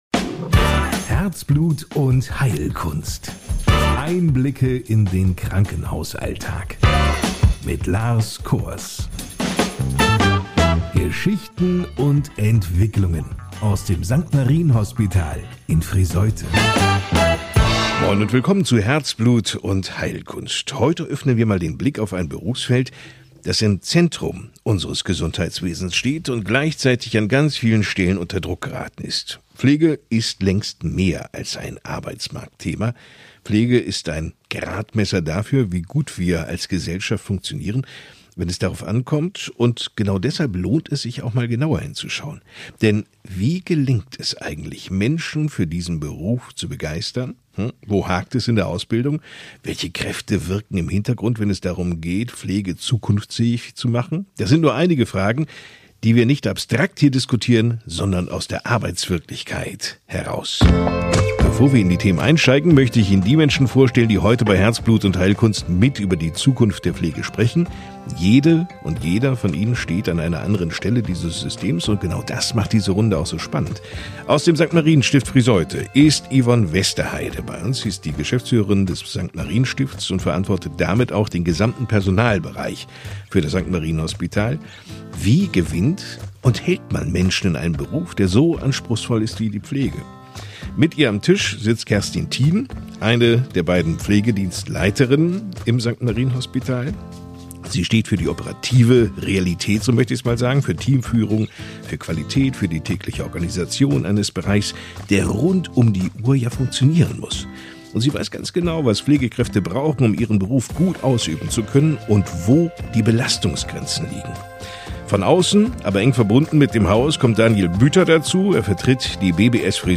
Im Mittelpunkt dieser Folge von "Herzblut und Heilkunst" steht das Spannungsfeld Pflege: Vier Gäste aus Management, Praxis, Ausbildung und Regionalentwicklung diskutieren, wie junge Menschen für den Pflegeberuf gewonnen und gehalten werden können, welche Belastungen im Alltag entstehen und welche re...